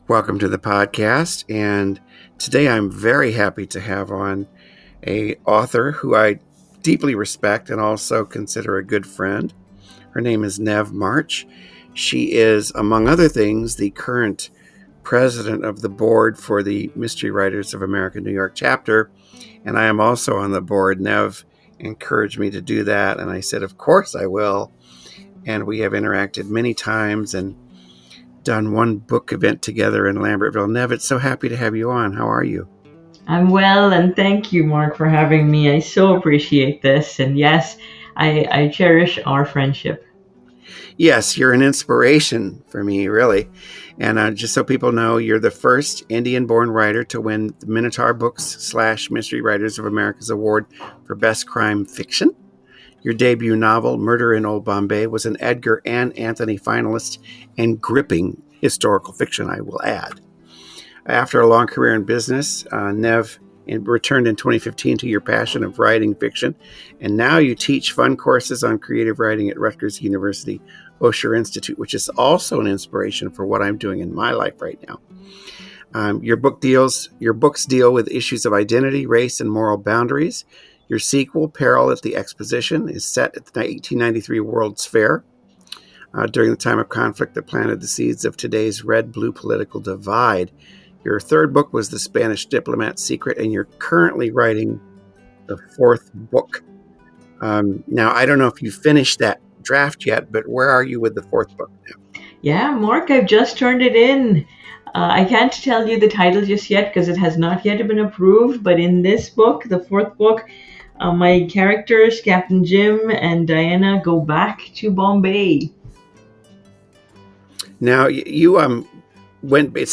NEW INTERVIEW FEATURE!